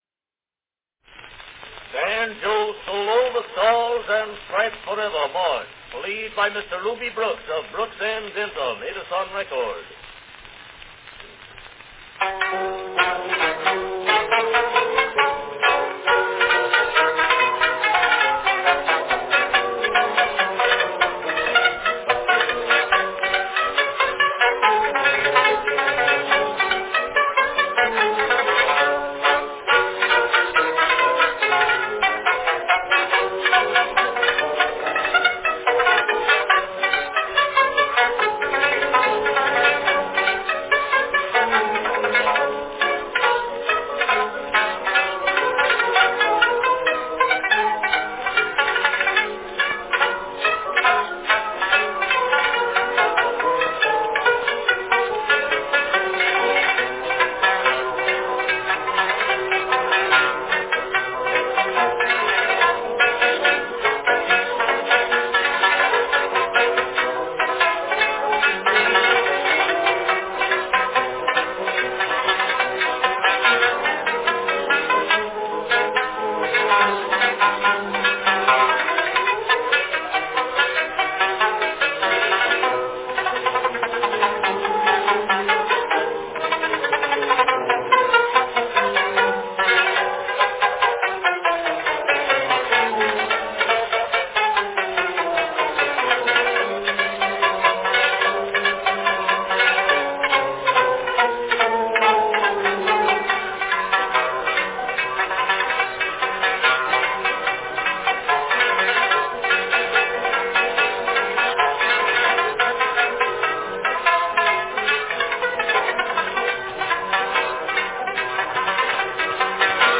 Cylinder of the Month
A nifty banjo rendition
Category Banjo solo
A very fine recording of the great Sousa march.